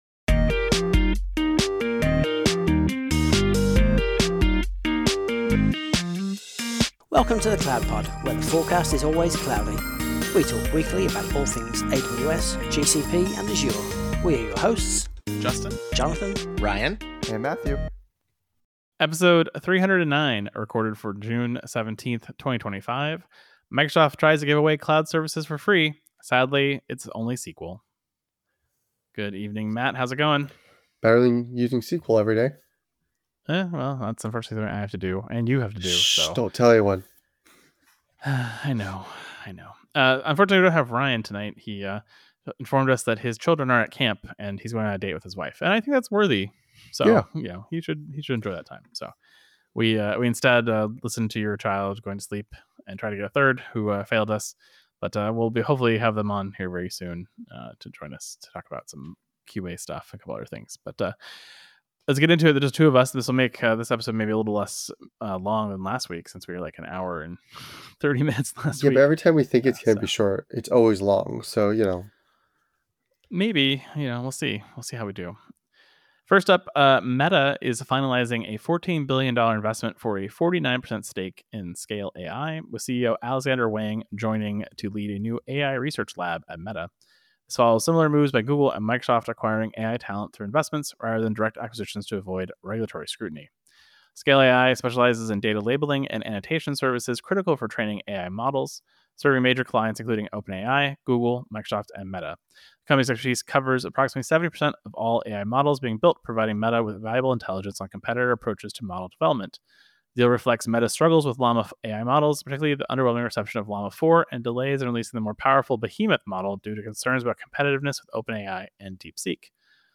Unfortunately, this one is also lullaby free.